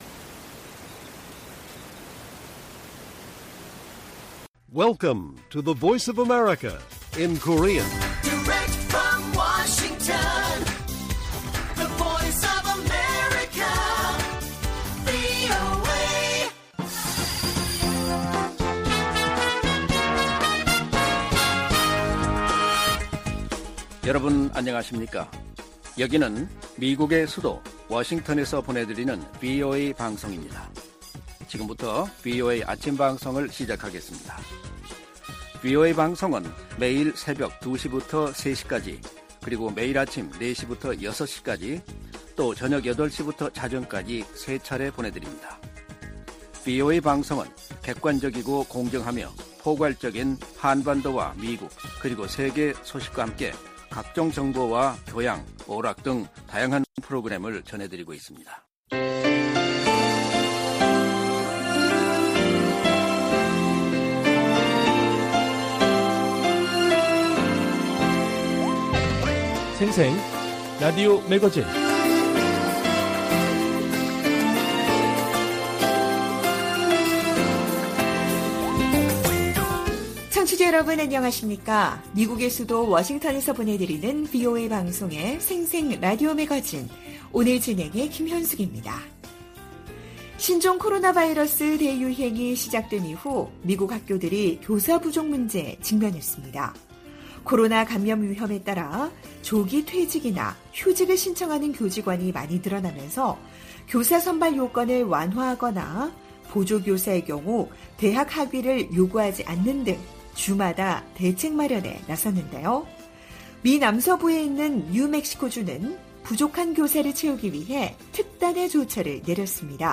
VOA 한국어 방송의 일요일 오전 프로그램 1부입니다. 한반도 시간 오전 4:00 부터 5:00 까지 방송됩니다.